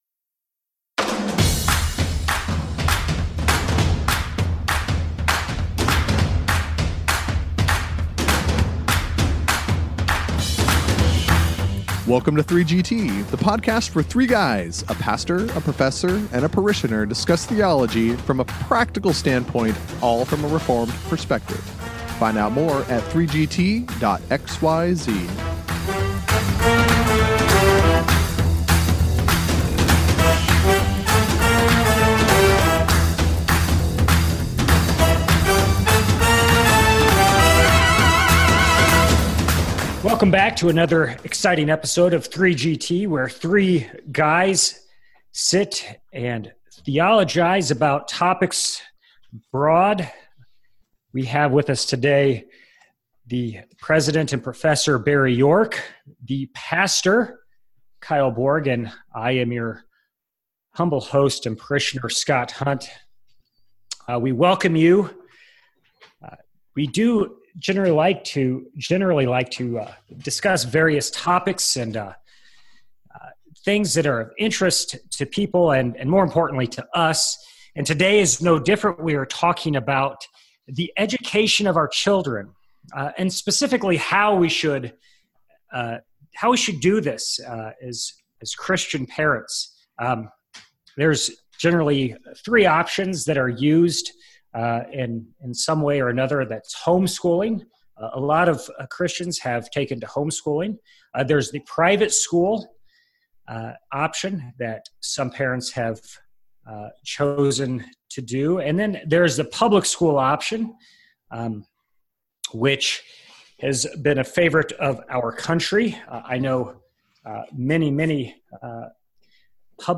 With our humble parishioner leading the way, the guys tackle this topic. The duty of parents to give their children a God-centered education is emphasized.
And along the way, the pastor speaks of the uniqueness of educating in a rural setting while the prof offers some grandfatherly advice.